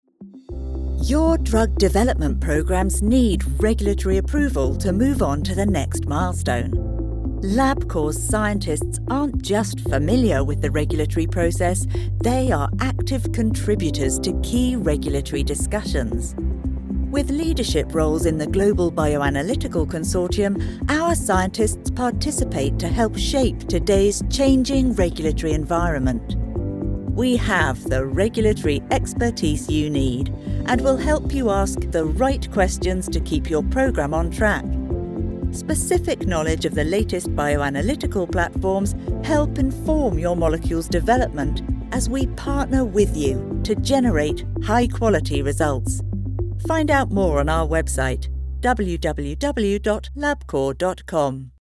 Mature, educated, warm and engaging British English voiceover.
Sprechprobe: Industrie (Muttersprache):
Mature, educated, warm and engaging British English voice artist.